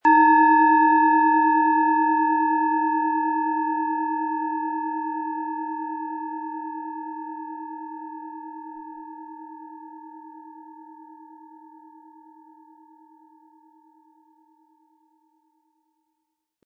Planetenschale® Erkenntnisse haben & Ausgeglichen fühlen mit Wasserstoffgamma, Ø 11,2 cm, 100-180 Gramm inkl. Klöppel
Im Audio-Player - Jetzt reinhören hören Sie genau den Original-Ton der angebotenen Schale.
PlanetentonWasserstoffgamma
HerstellungIn Handarbeit getrieben
MaterialBronze